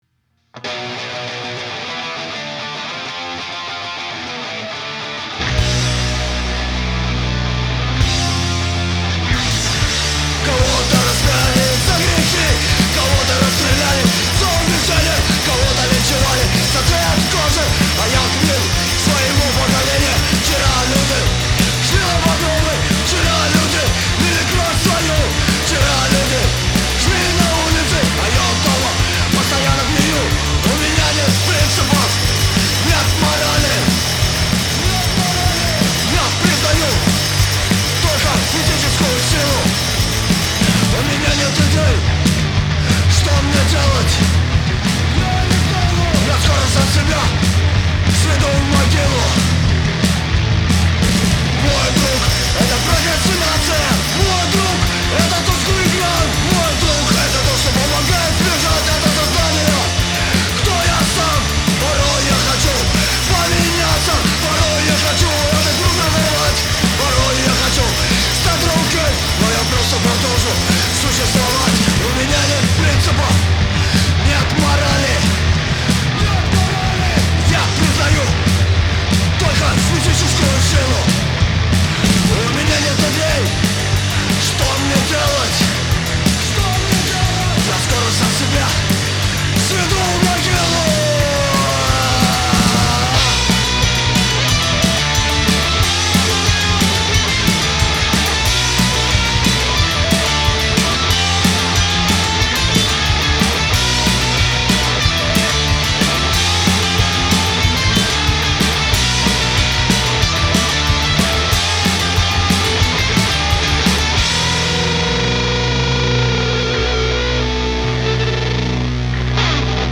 хардкор-группы